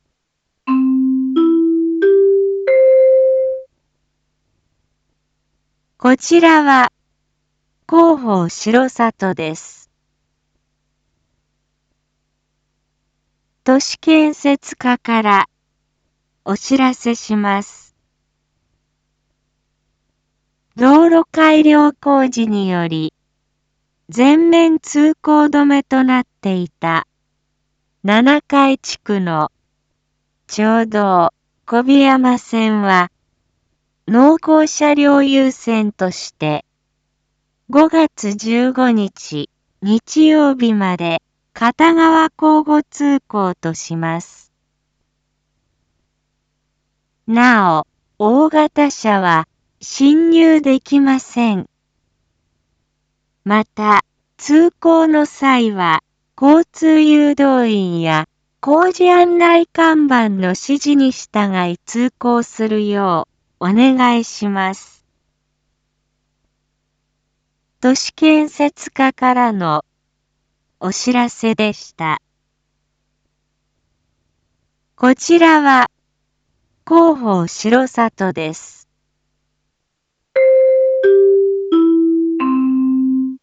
Back Home 一般放送情報 音声放送 再生 一般放送情報 登録日時：2022-05-12 19:01:25 タイトル：R4.5.12 19時放送分 インフォメーション：こちらは広報しろさとです。